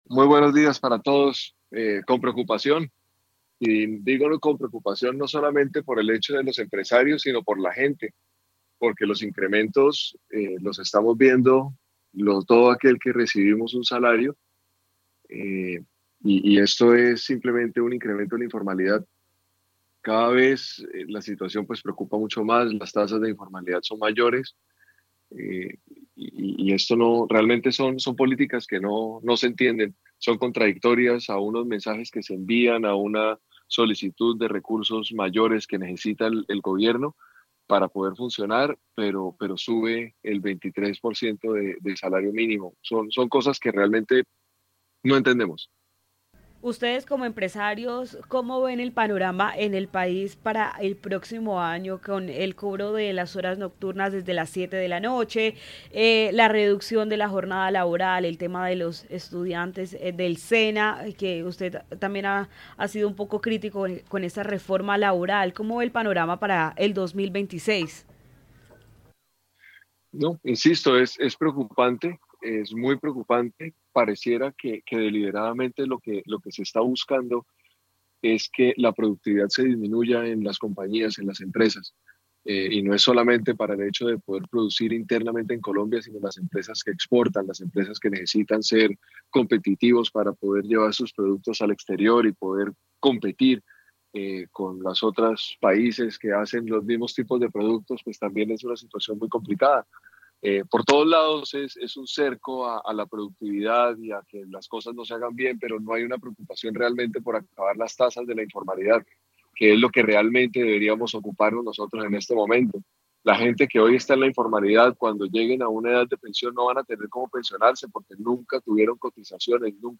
empresario